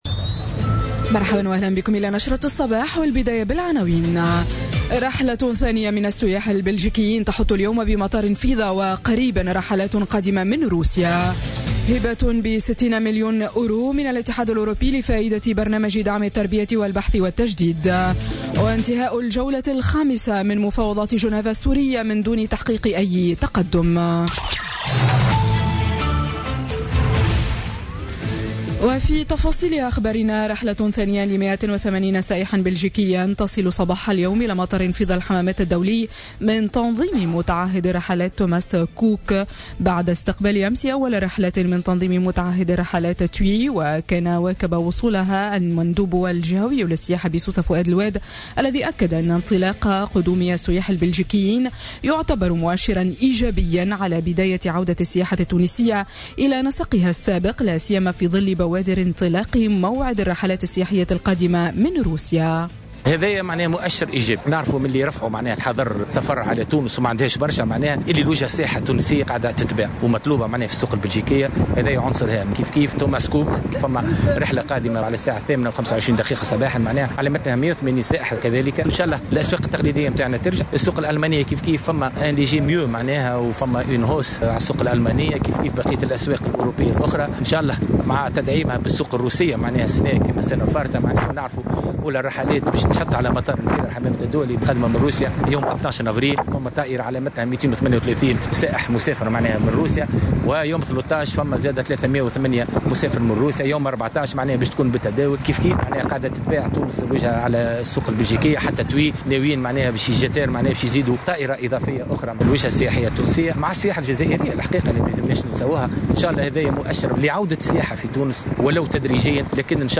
نشرة أخبار السابعة صباحا ليوم السبت غرة أفريل 2017